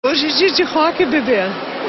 Atriz Christiane Torloni soltou o famoso "Hoje é dia de rock, bebê" durante Rock In Rio 2011.